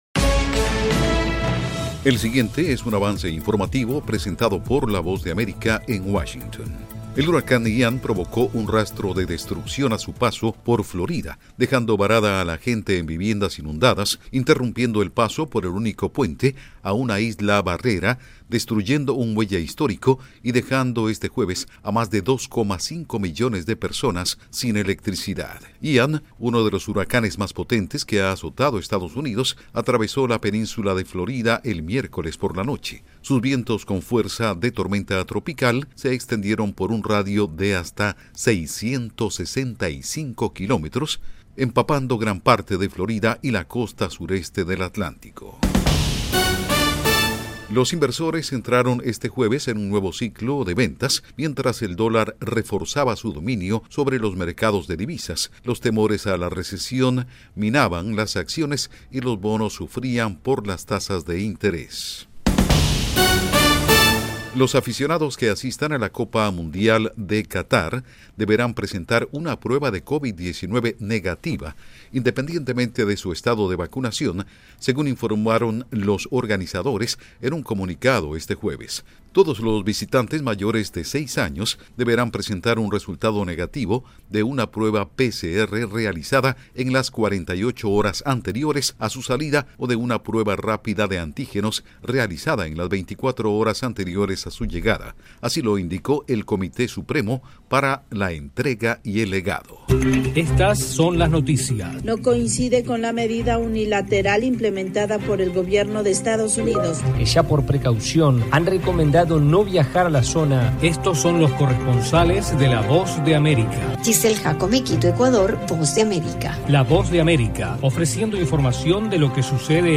AVANCE INFORMATIVO 2 PM